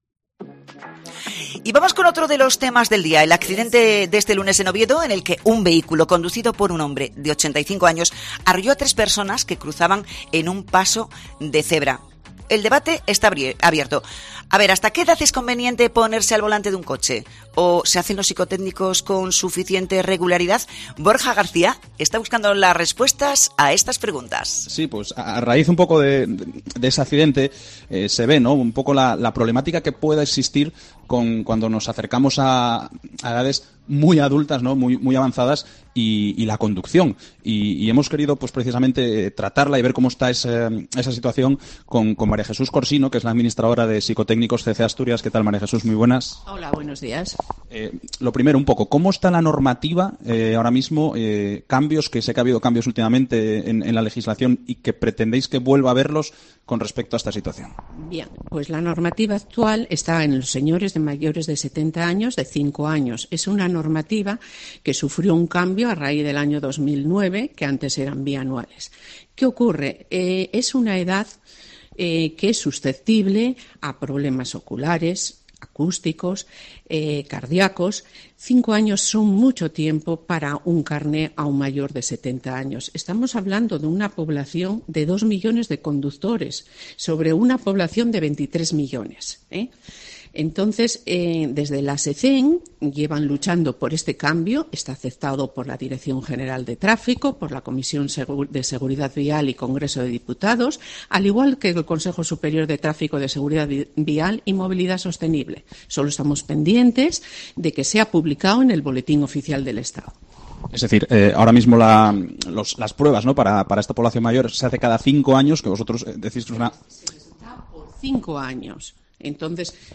Enlace a noticia en radio